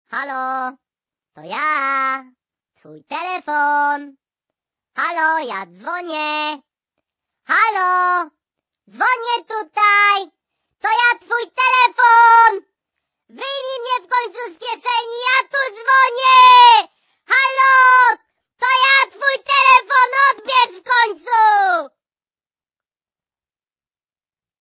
Popularny młodzieowy dzwonek z kategorii śmieszne.